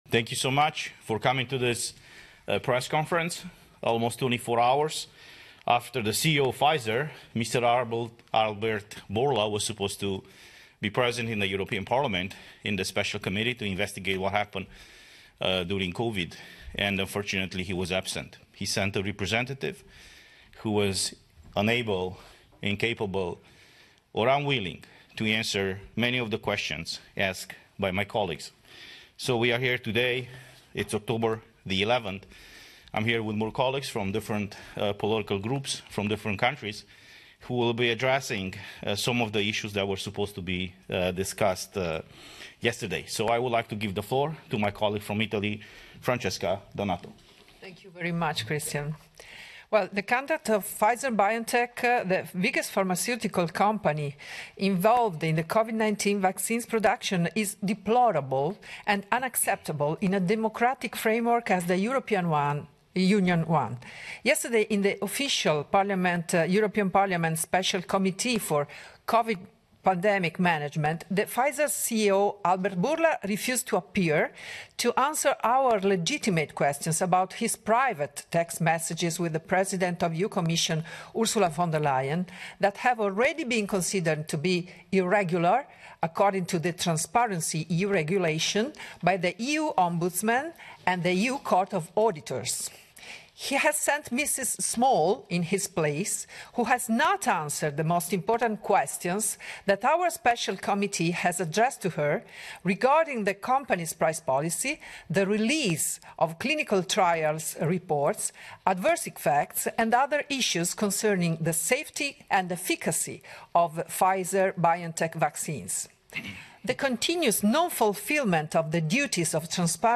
Press conference after Pfizer CEO Albert Bourla refused to answer in front of European Parliament